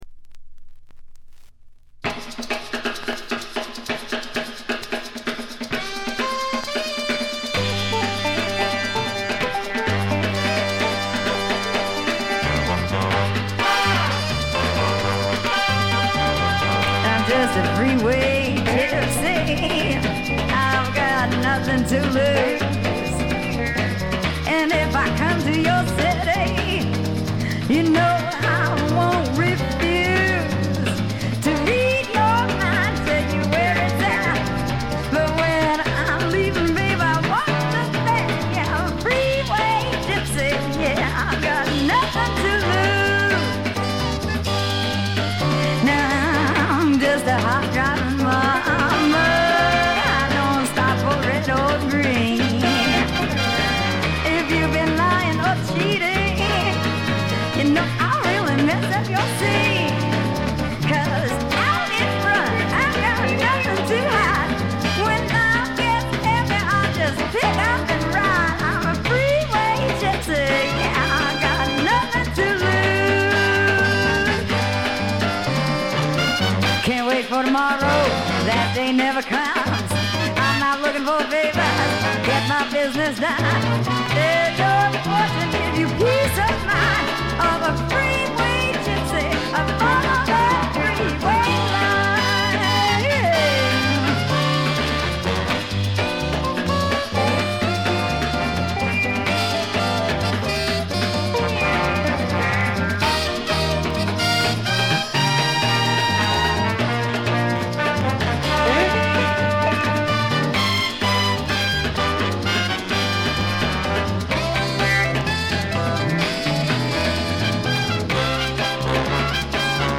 ところどころでチリプチ（特にB1は多め）。
スワンプ、R&B、ジャズ、ブルース、サイケのごった煮で
ホーン・セクションを含む大世帯のバンドを狭いライヴ酒場で聴いているような強烈な圧力があります。
試聴曲は現品からの取り込み音源です。
Vocals